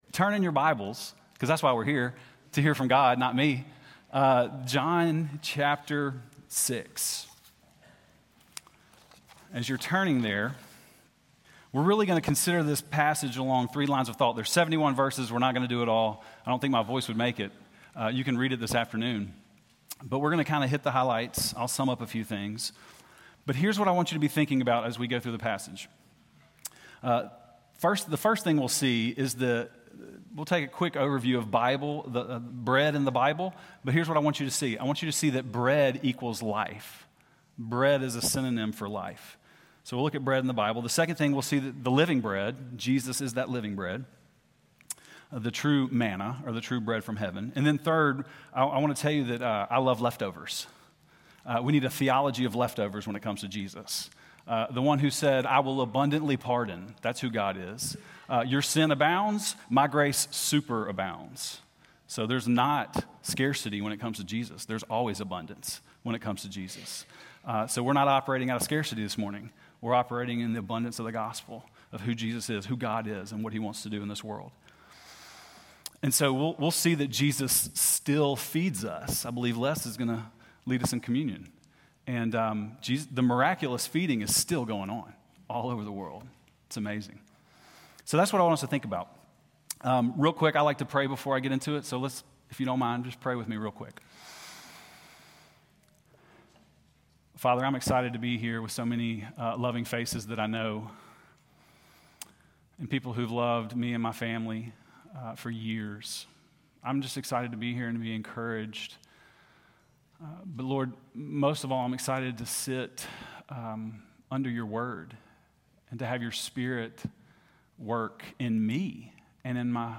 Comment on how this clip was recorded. CPC-Worship-6.8.25.mp3